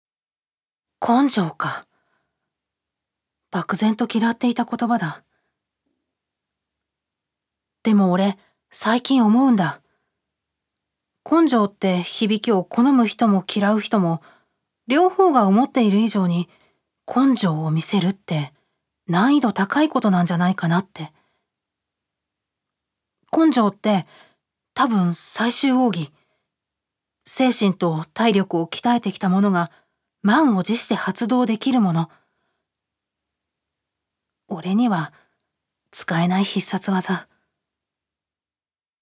女性タレント
セリフ４